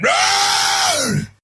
yawn.wav